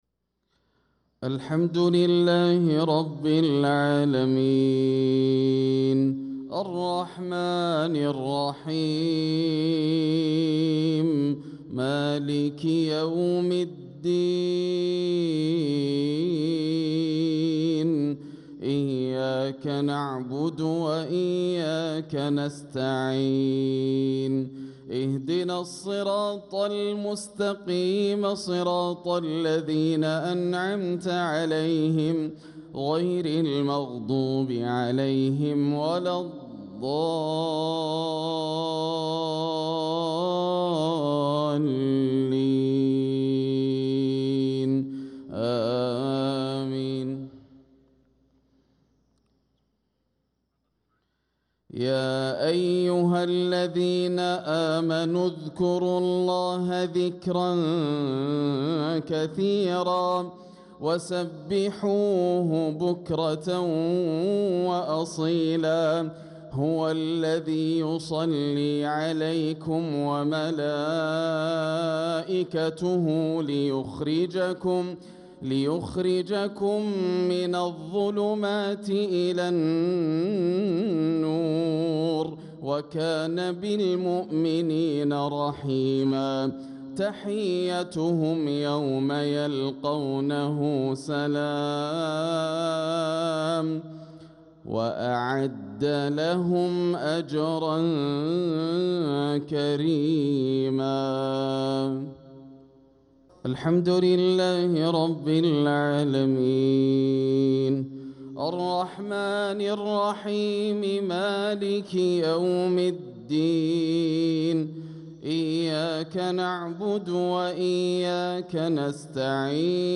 صلاة المغرب للقارئ ياسر الدوسري 22 ربيع الآخر 1446 هـ
تِلَاوَات الْحَرَمَيْن .